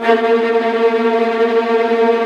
VIOLINT CN-R.wav